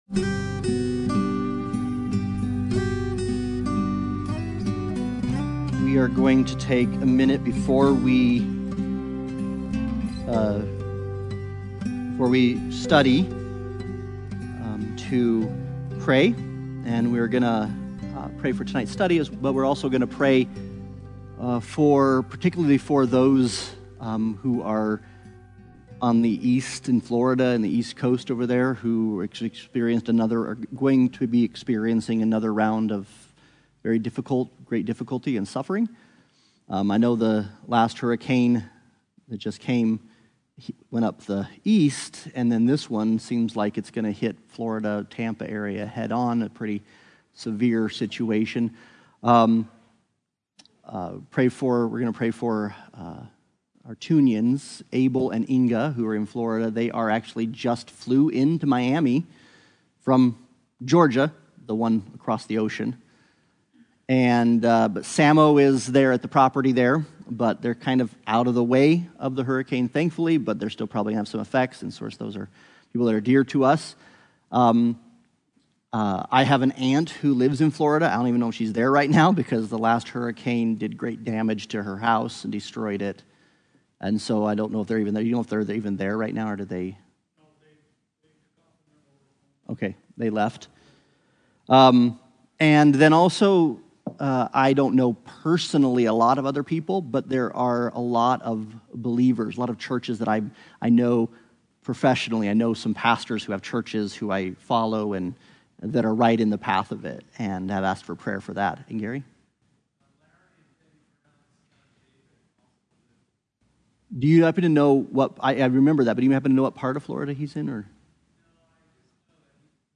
The Gospel According to Mark Service Type: Sunday Bible Study « Grow Up